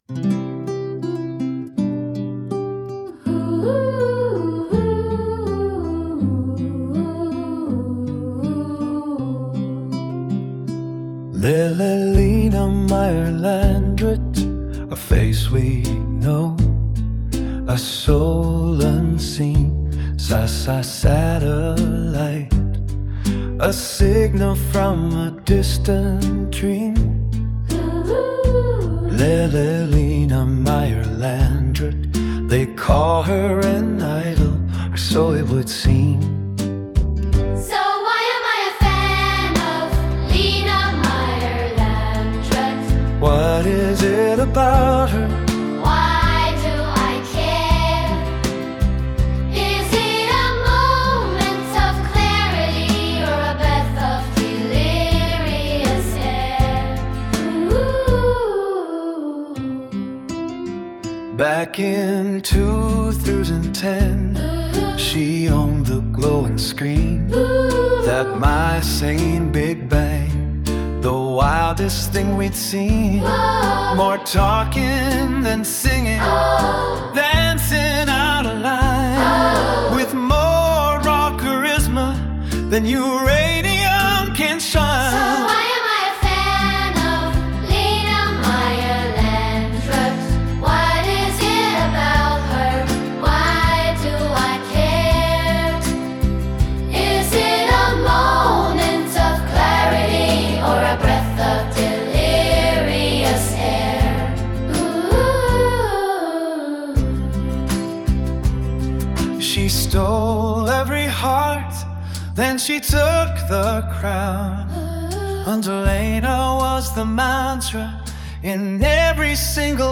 The (Lena-) Fan – Kinderchor, akustisches Piano, akustische Gitarre